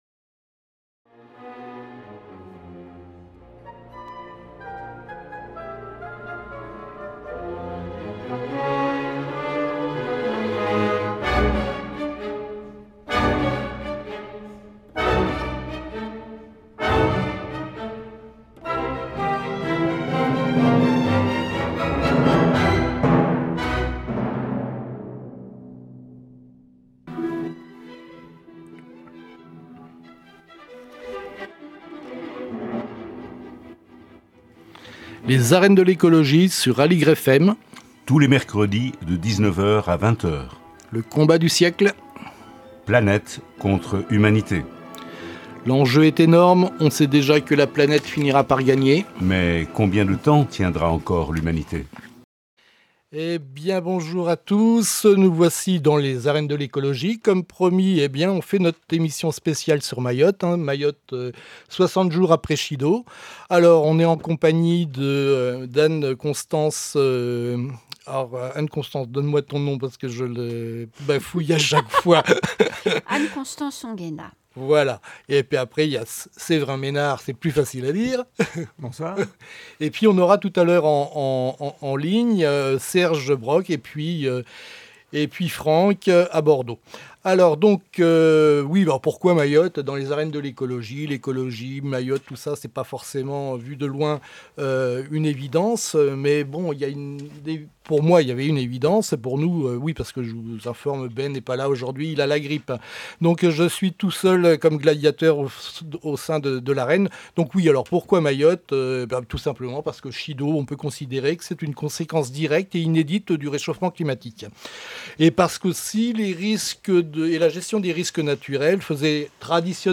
Dans cet épisode spécial, nous croisons les regards de journalistes, d’acteurs humanitaires et de spécialistes de la cartographie pour mieux comprendre les enjeux et les solutions.